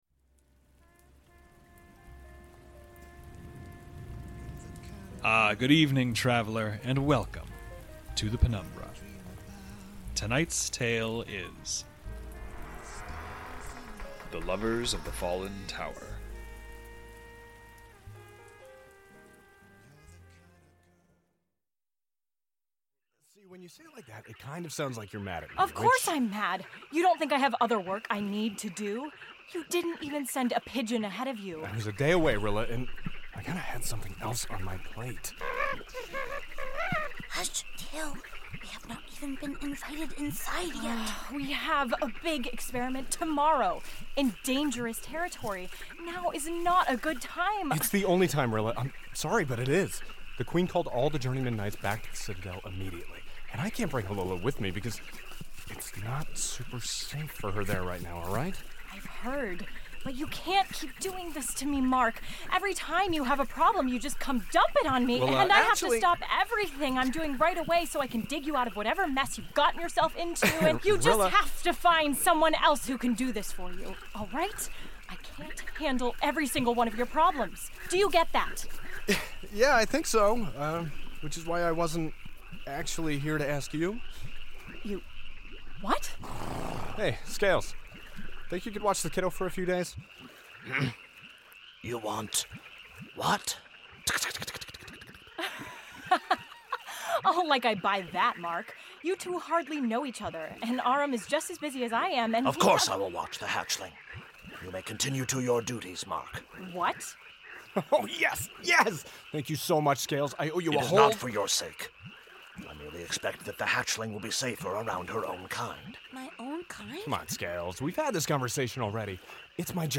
Fiction, Thirst, Second Citadel, Juno Steel, Scifi, Horror, Audio Drama, Rusty Quill, Audio Fiction, The Penumbra Podcast, Penumbra, Thirst Podcast, Performing Arts, Arts, Comedy, Science Fiction